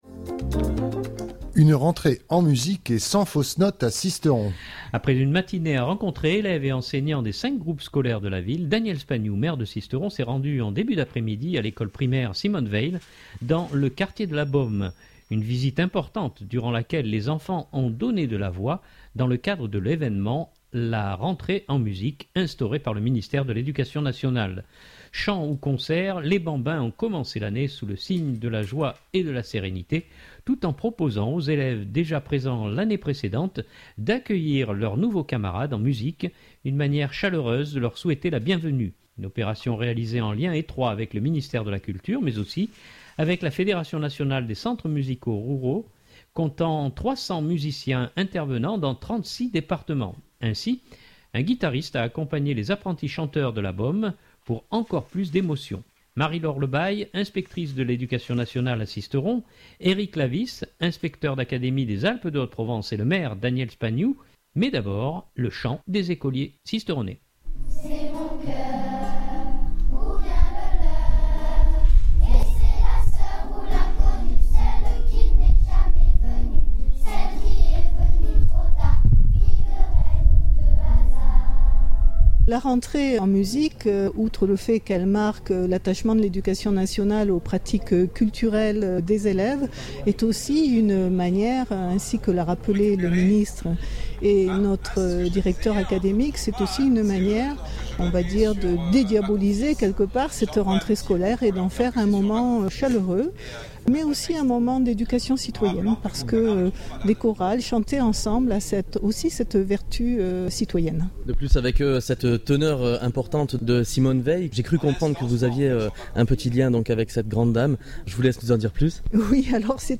Une visite importante durant laquelle les enfants ont donné de la voix, dans le cadre de l’événement « La rentrée en musique » instauré par le ministère de l’ ‘éducation nationale.
Ainsi, un guitariste a accompagné les apprentis chanteurs de la Baume pour encore plus d’émotion.
le chant des écoliers sisteronais.